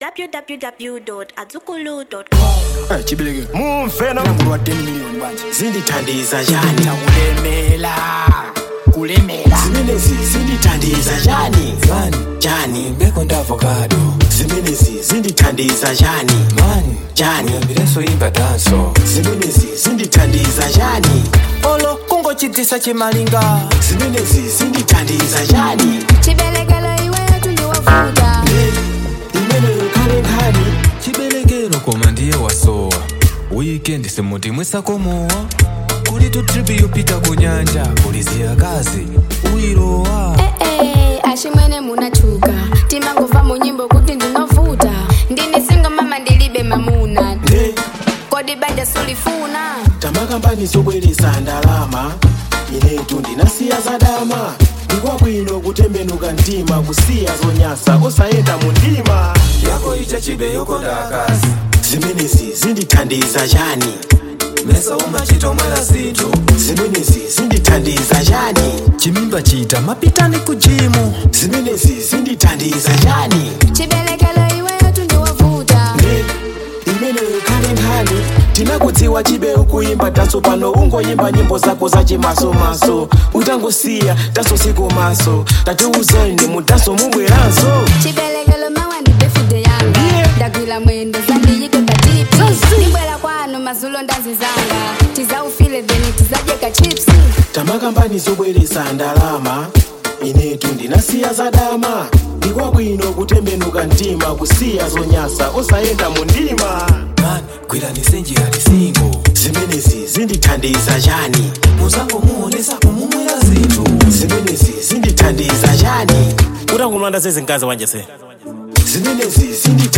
Genre Reggae & Dancehall